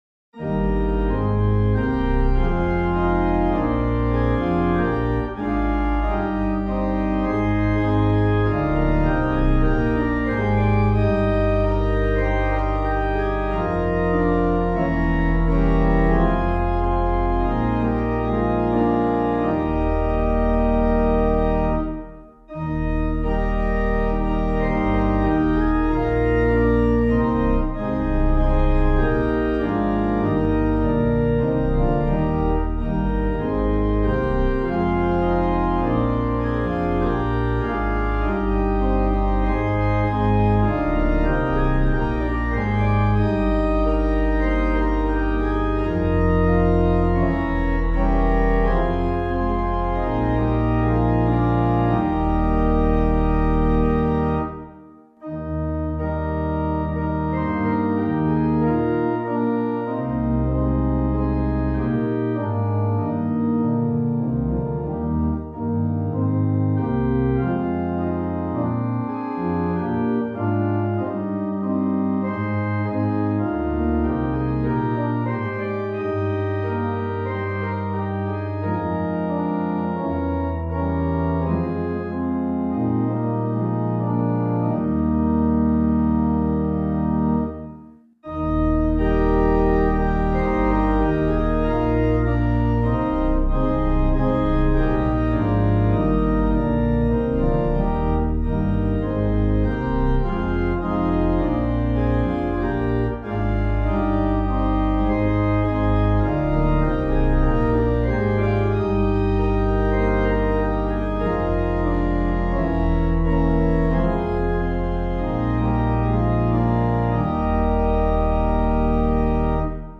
Hymn suitable for Catholic liturgy.